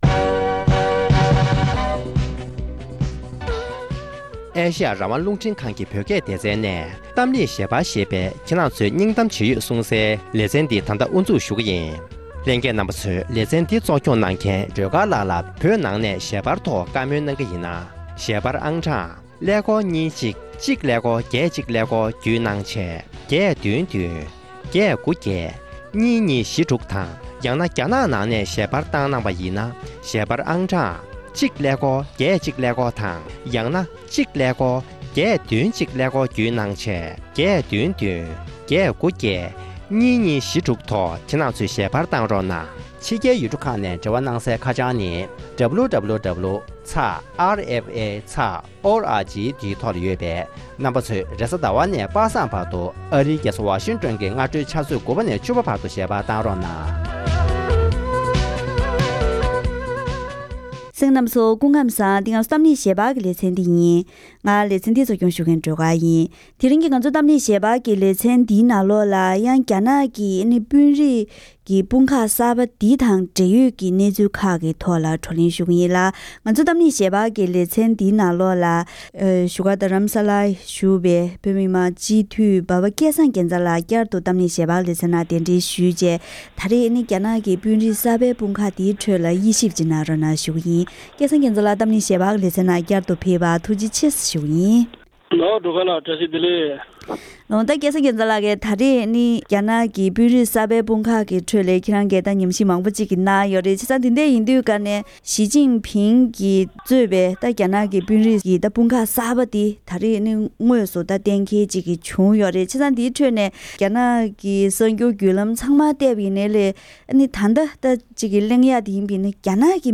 དེ་རིང་གི་གཏམ་གླེང་ཞལ་པར་ལེ་ཚན་ནང་།